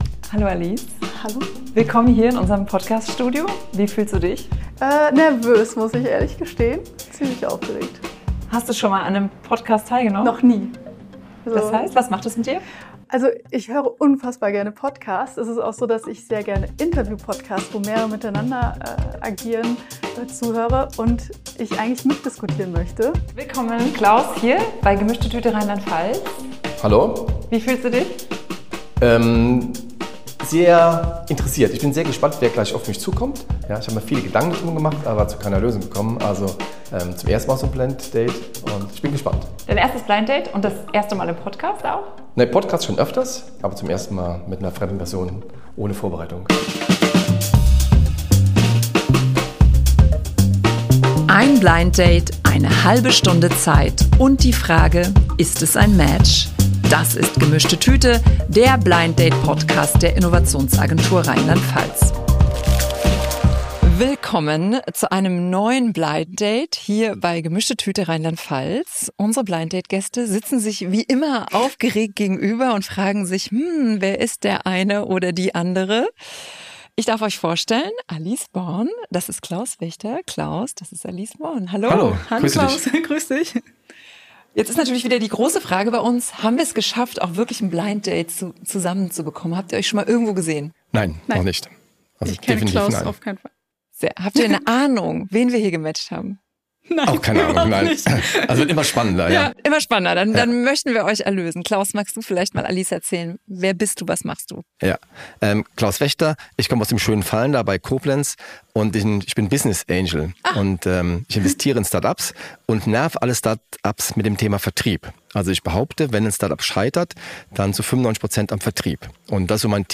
Im Podcast der Innovationsagentur RLP treffen sich zwei Persönlichkeiten, die sich bisher nicht kennen, und tauschen sich über ihre unterschiedlichen Perspektiven aus. Das Besondere: Sie bringen ein Geschenk mit, das etwas über sie erzählt.